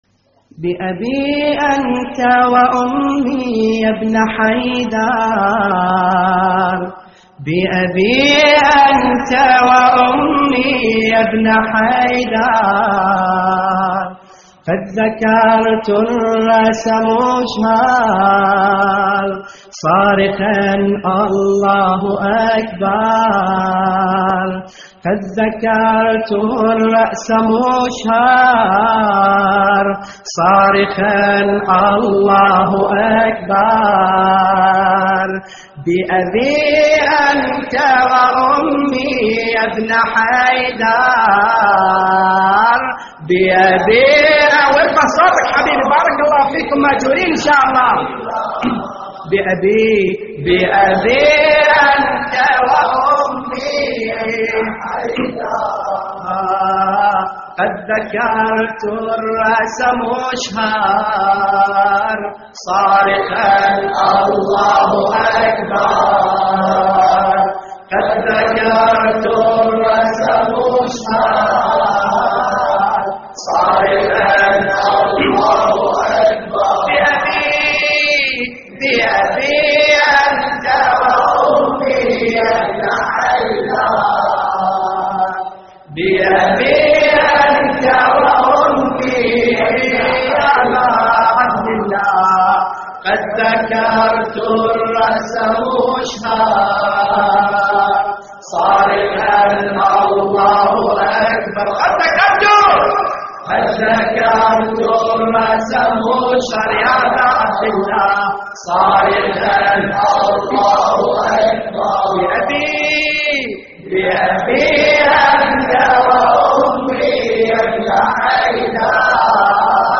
تحميل : بأبي انت وامي يابن حيدر / مجموعة من الرواديد / اللطميات الحسينية / موقع يا حسين